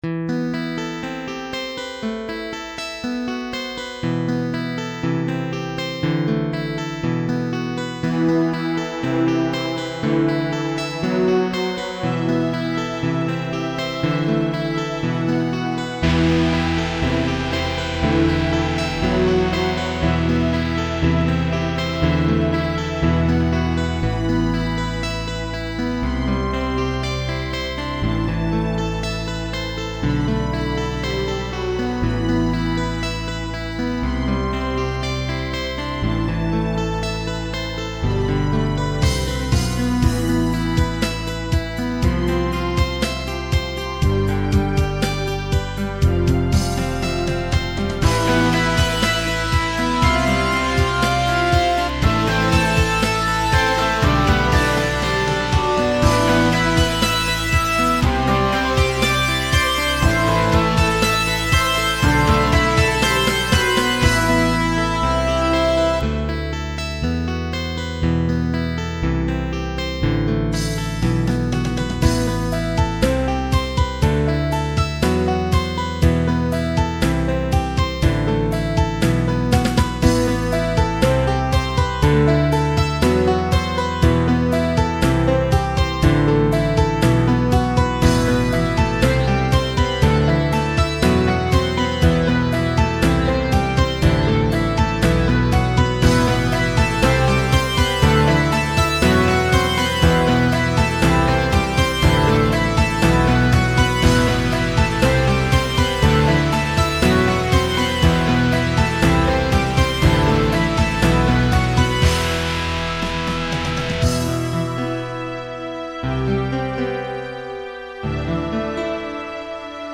PROGRESSIVE ROCK MUSIC